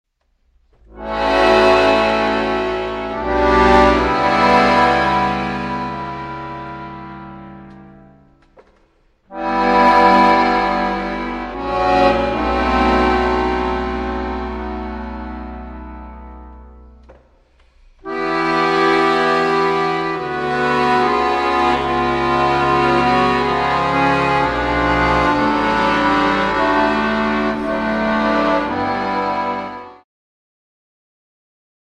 Lent